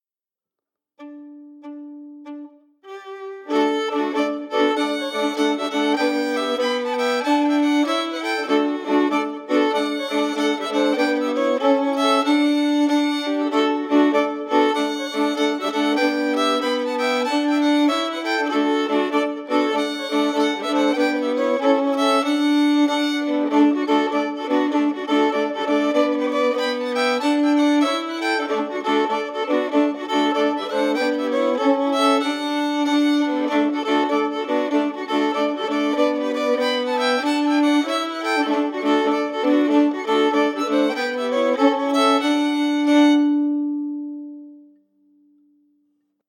Key: D
Form: Jig
Harmony emphasis
Region: Cape Breton, Canada